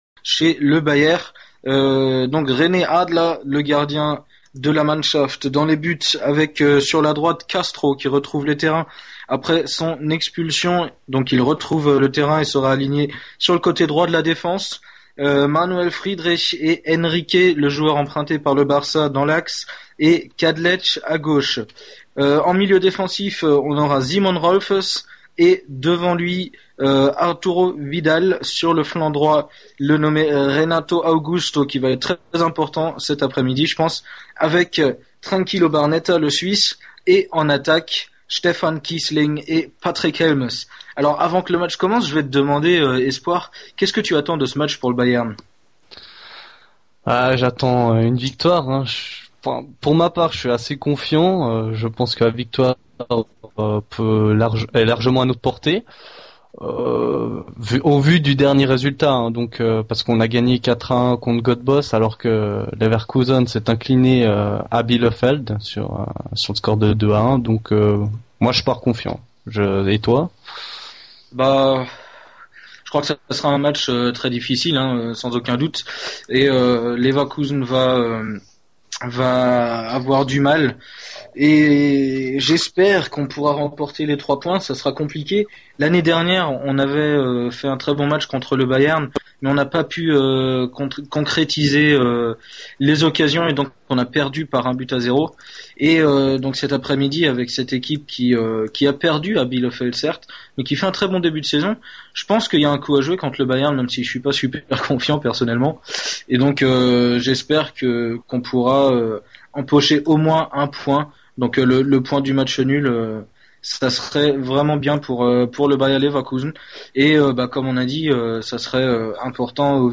C'est une première sur Génération Soccers, un match est commenté en intégralité et en direct